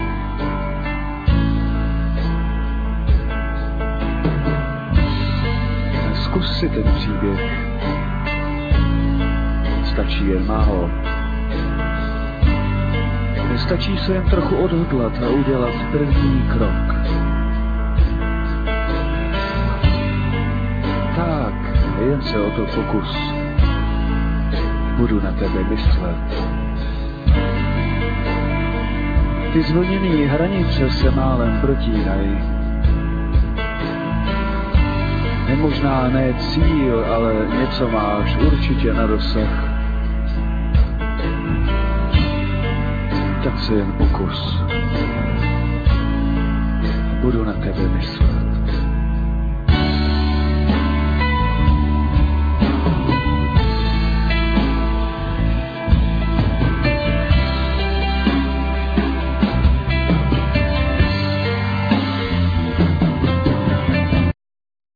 Vocal,Piano
Drums,Nastroje,Djembe
Bass
Saxophone